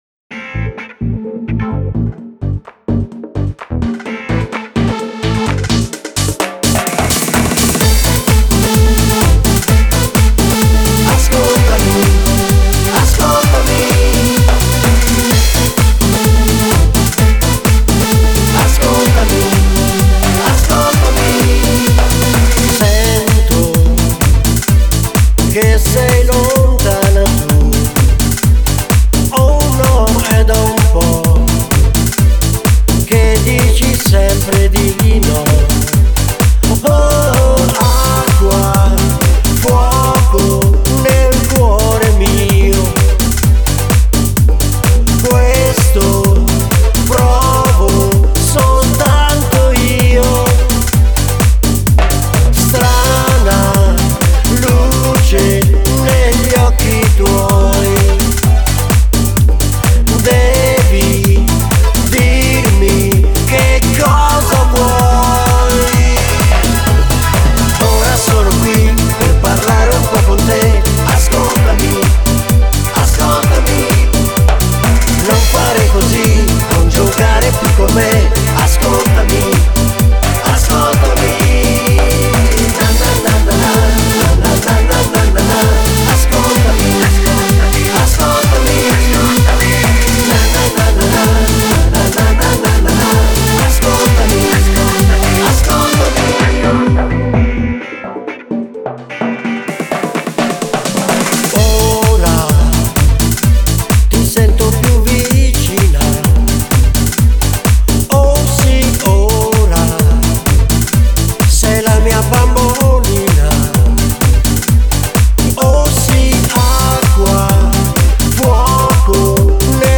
Tiburon dance